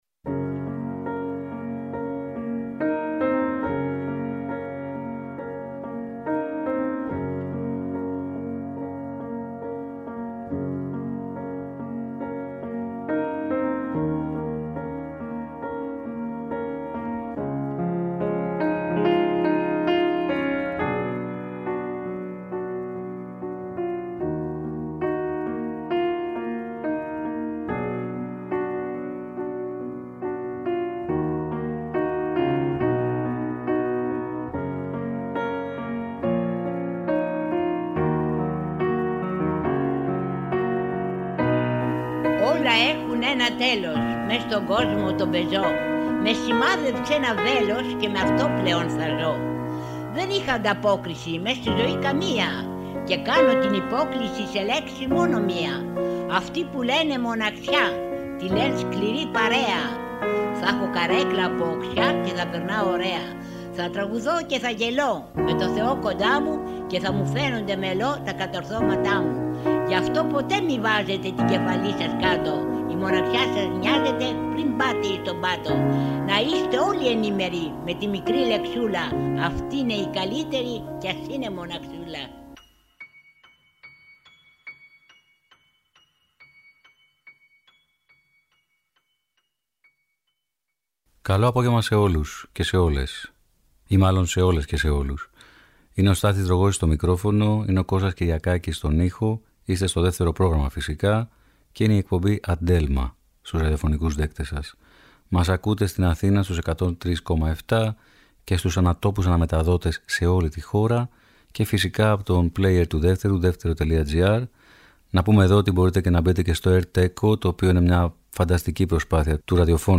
ανθολογεί τα πιο κλασικά ελληνικά τραγούδια.